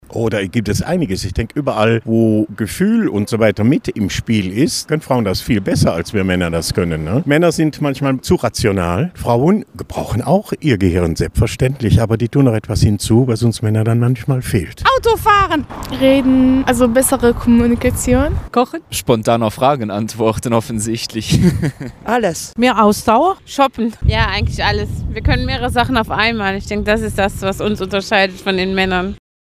Umfrage zur Frauenpower in Ostbelgien